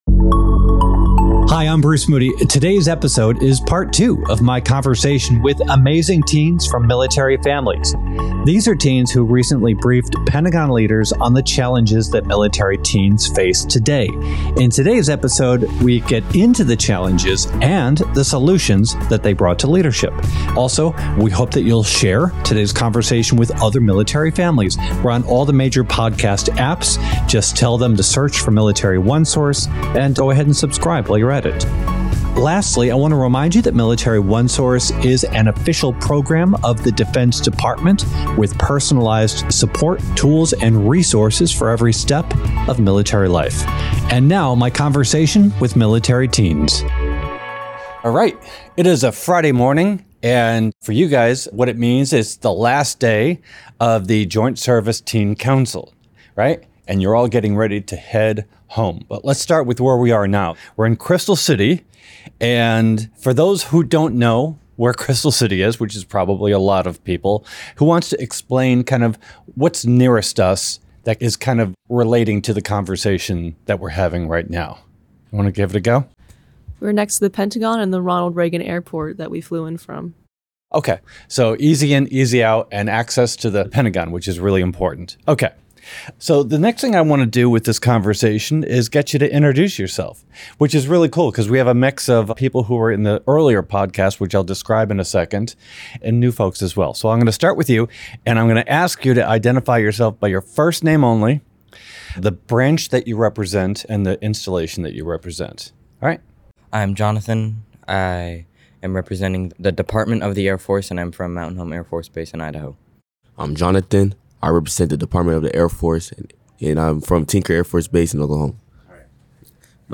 Military-connected teens share real-world challenges and solutions in part two of this powerful discussion on life, leadership and youth advocacy.